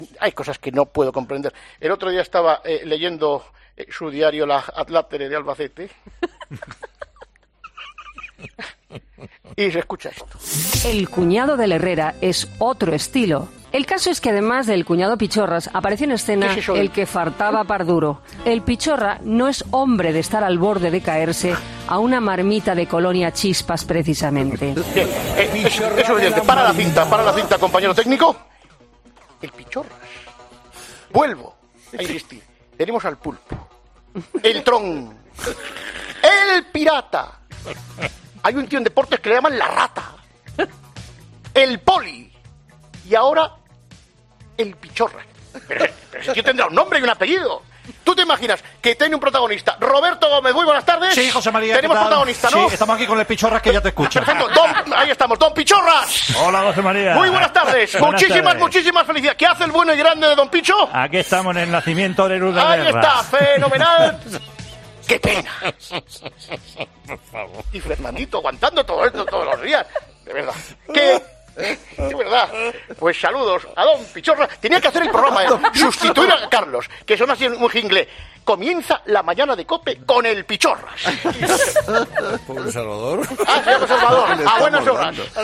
Te recordamos uno de los mejores momentos del Grupo Risa en Herrera en COPE
El delirante discurso de García hace que todos los presentes se partan de risa, imaginando ese programa presentado por el Pichorras que bien podría llamarse también 'Pichorras en COPE'.